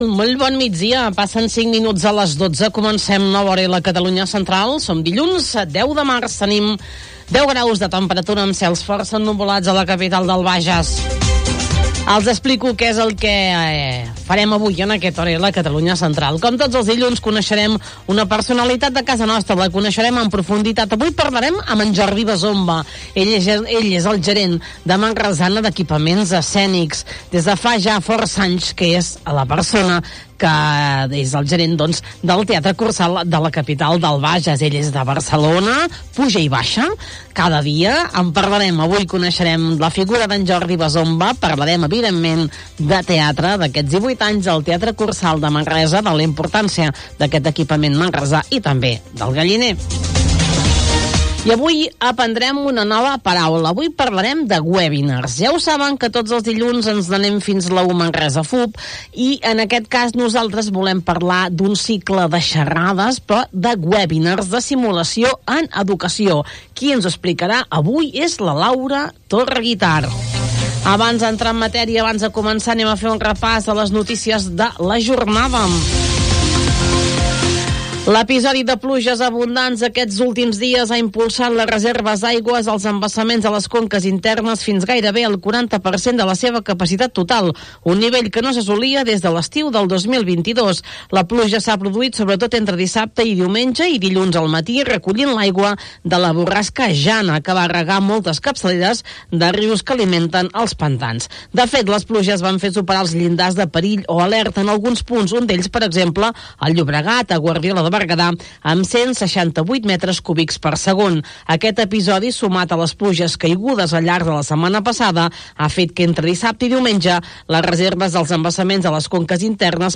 1 Hora L del 10/3/2025 1:00:00 Play Pause 1d ago 1:00:00 Play Pause Later Afspelen Later Afspelen Lijsten Vind ik leuk Leuk 1:00:00 Magazine d'actualitat de la Catalunya Central amb entrevistes polítiques, socials i culturals. L'Hora L escolta les problemàtiques del territori, amb connexions d'actualitat i descobrint el patrimoni, la gastronomia i les festes de les comarques centrals.